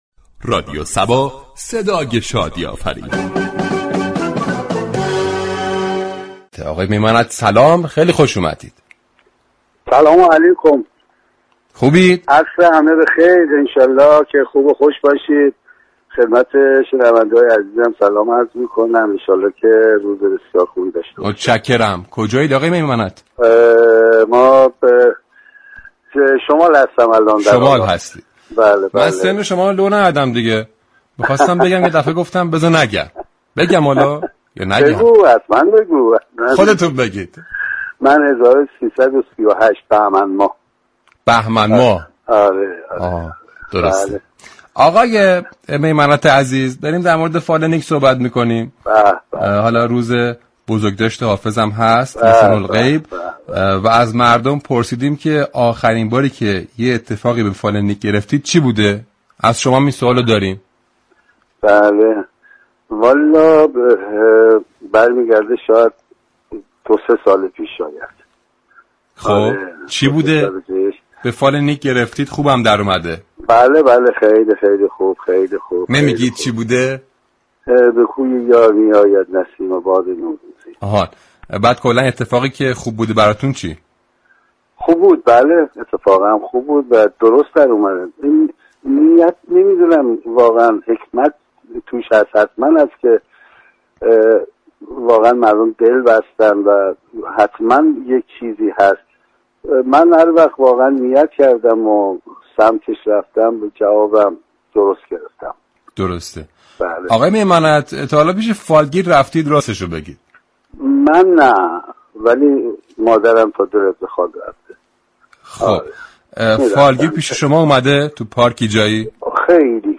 برنامه زنده عصر صبا با سیروس میمنت بازیگر و فیلمنامه‌نویس ایرانی در خصوص فال نیك زدن گفتگو كرد.